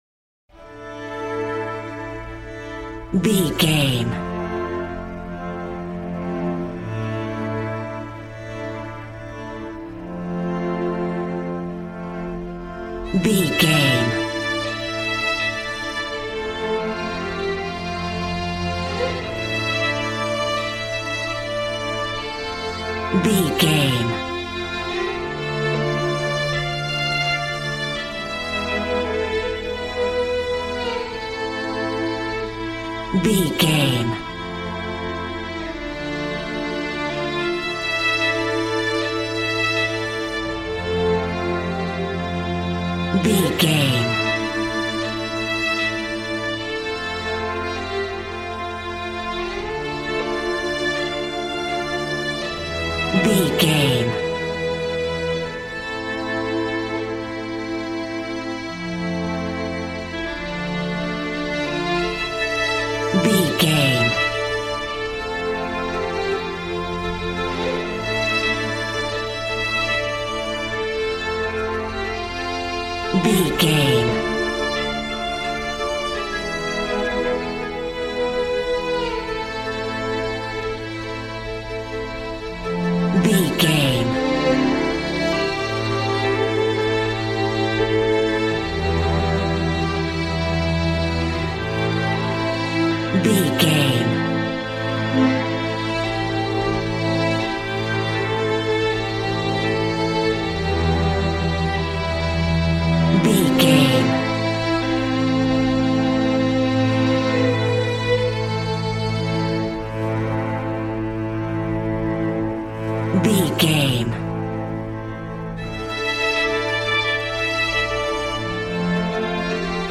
Modern film strings for romantic love themes.
Regal and romantic, a classy piece of classical music.
Aeolian/Minor
regal
cello
violin
brass